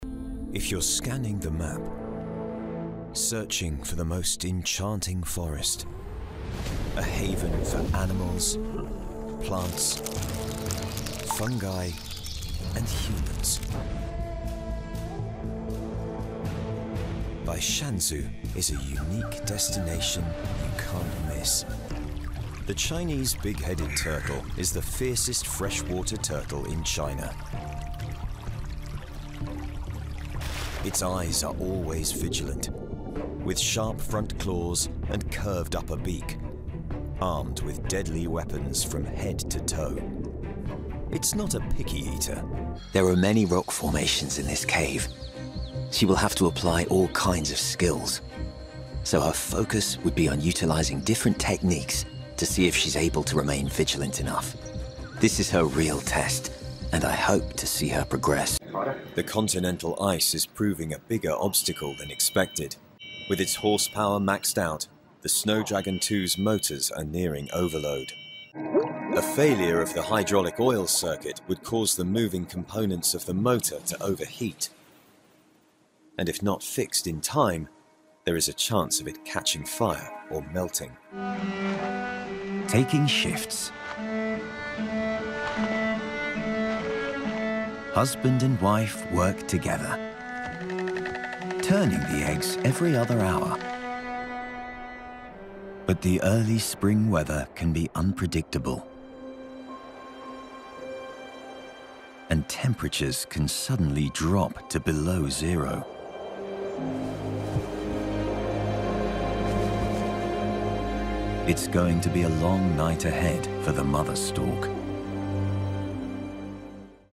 DOCUMENTARY SHOWREEL
His deep, authentic RP voice lends youthful gravitas to commercials and narration, while his versatile accent range makes him a standout character actor for games, animation, and radio.
Male